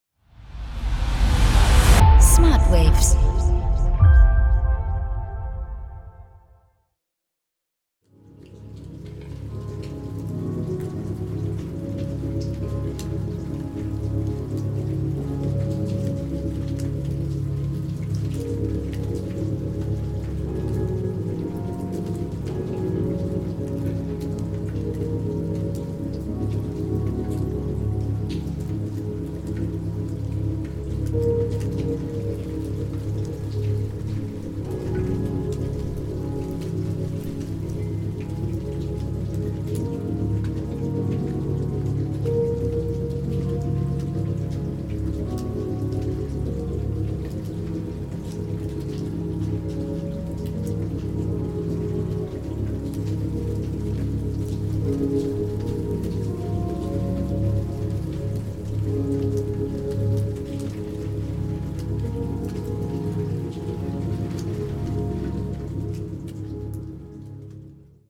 Isochrone Beats